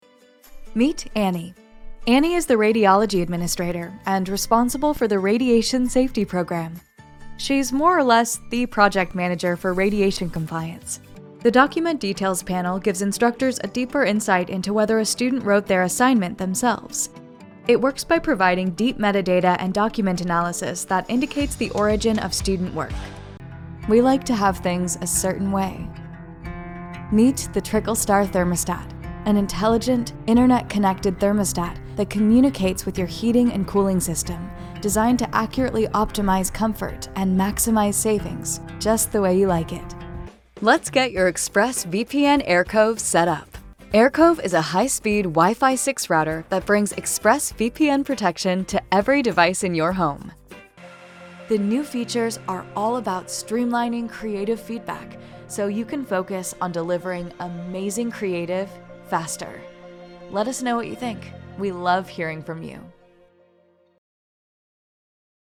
E-Learning and Explainer Video Reel